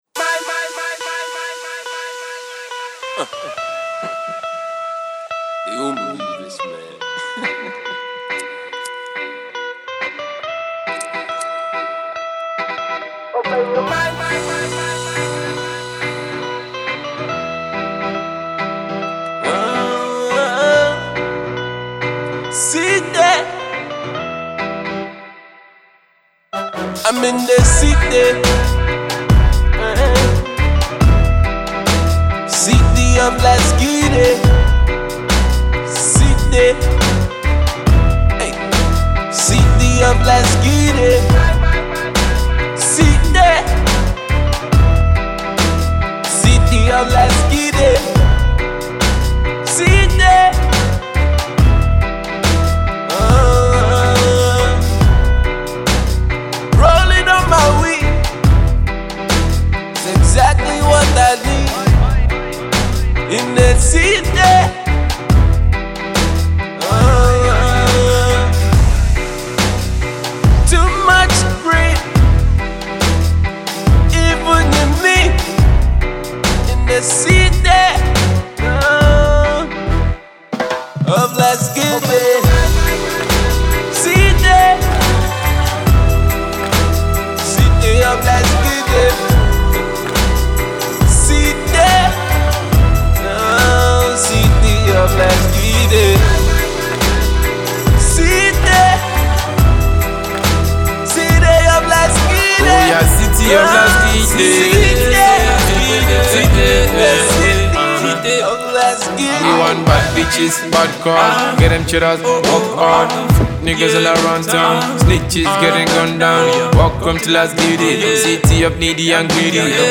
Street Blues